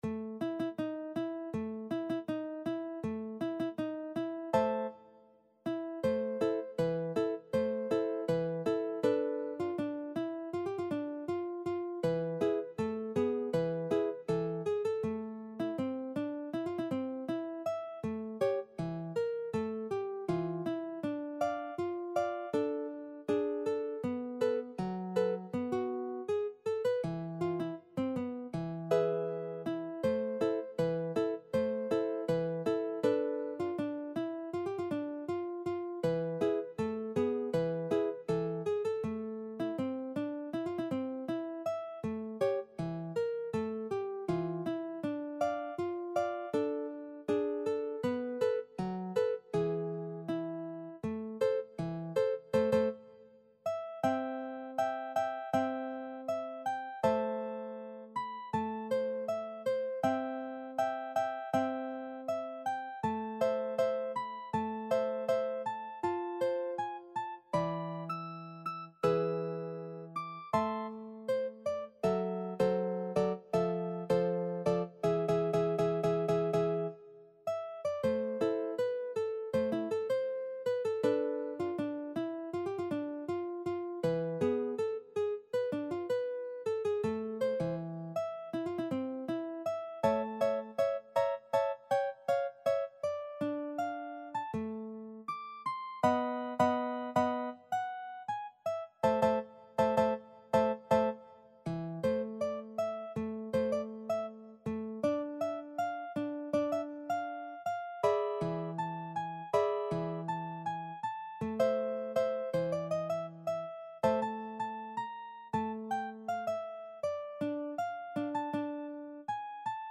歌謡曲・演歌
楽譜の音源（デモ演奏）は下記URLよりご確認いただけます。
（この音源はコンピューターによる演奏ですが、実際に人が演奏することで、さらに表現豊かで魅力的なサウンドになります！）